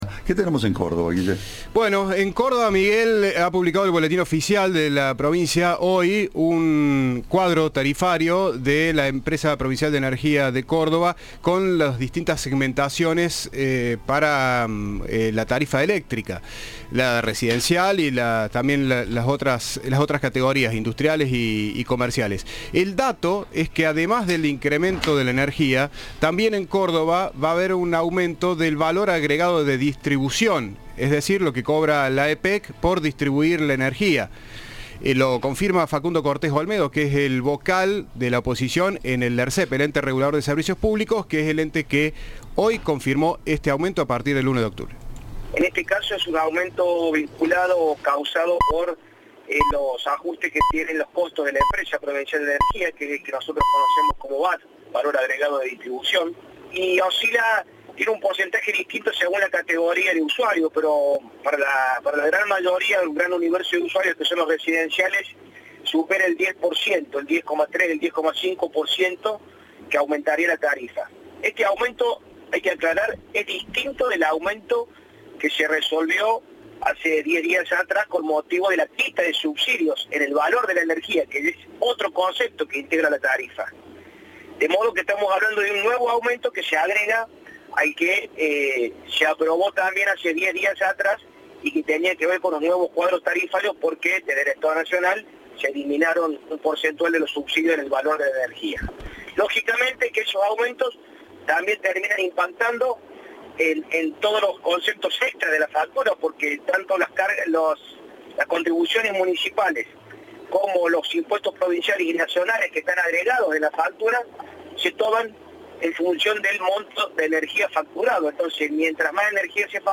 En diálogo con Cadena 3, el vocal de la oposición en el Ente Regulador de Servicios Públicos (Ersep), Facundo Cortés Olmedo, dijo que se trata de un incremento vinculado a los ajustes de las empresas en temas de provisión de energía y cuya cifra oscila según la categoría de usuario.